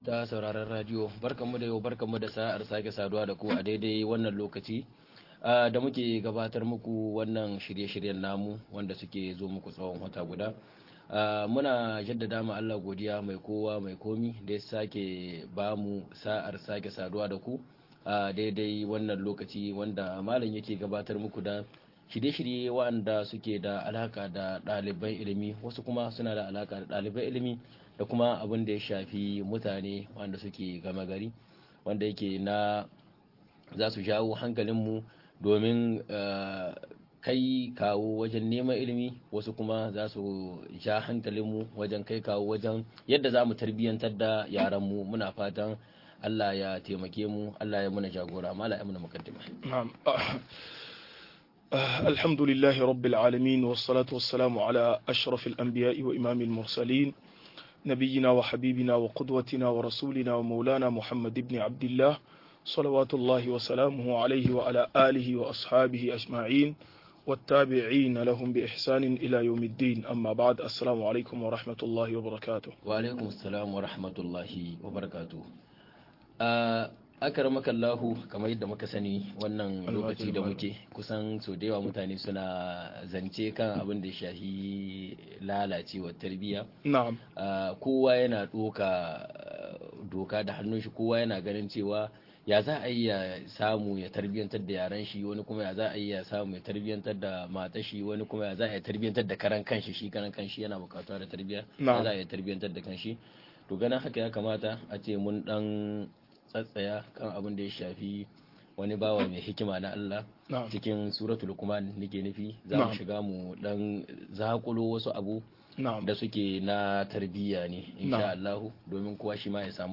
Fa'idodin tarbiyya cikin suratu Luqmaan - MUHADARA